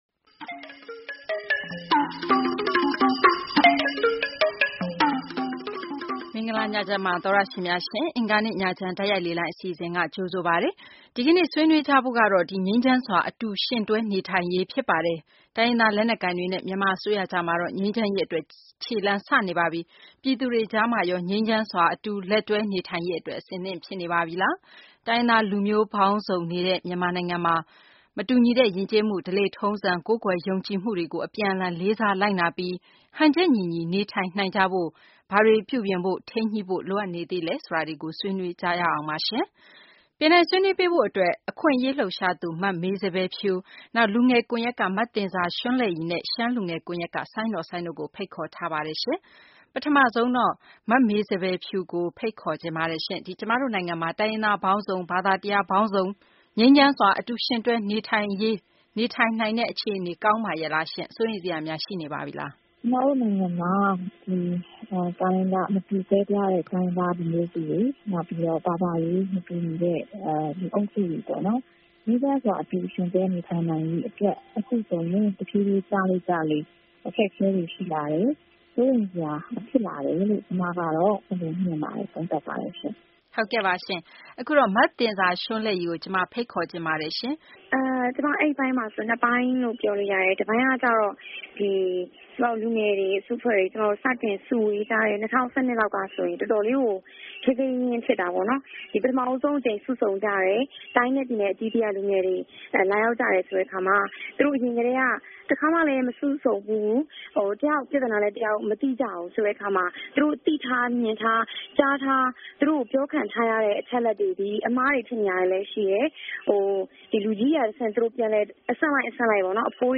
တိုင်းရင်းသား လူမျိုးပေါင်းများစွာ အတူယှဉ်တွဲ နေထိုင်တဲ့ မြန်မာနိုင်ငံမှာ ပြည်သူတွေကြား မတူညီတဲ့ ယဉ်ကျေးမှု ဓလေ့ထုံးစံ ကိုးကွယ်ယုံကြည်မှုတို့ကို အပြန်အလှန် လေးစားလိုက်နာပြီး ဟန်ချက်ညီညီ နေထိုင်နိုင်ရေးနဲ့ ပတ်သက်ပြီး ဆွေးနွေးထားတဲ့ အင်္ဂါနေ့ ညချမ်းတိုက်ရိုက်လေလှိုင်း အစီအစဉ်ကို နားဆင်နိုင်ပါတယ်။